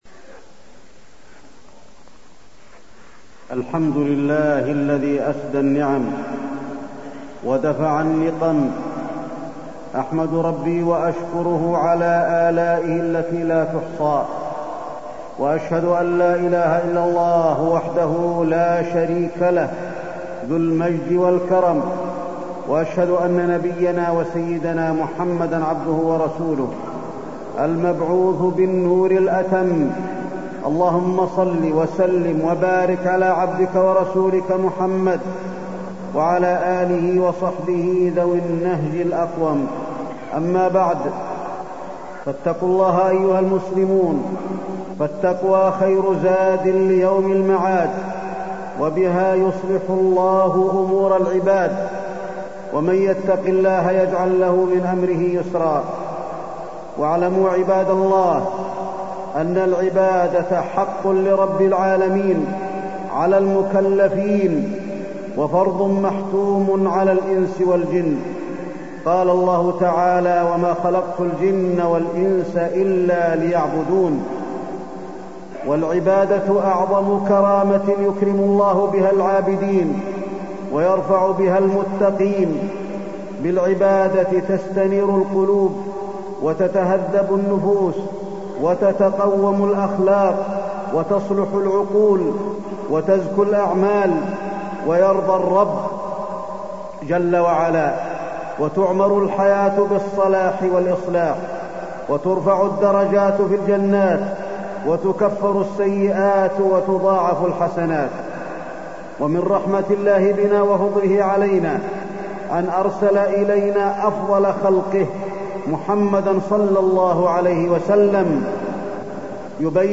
تاريخ النشر ٢١ ذو القعدة ١٤٢٣ هـ المكان: المسجد النبوي الشيخ: فضيلة الشيخ د. علي بن عبدالرحمن الحذيفي فضيلة الشيخ د. علي بن عبدالرحمن الحذيفي العبادة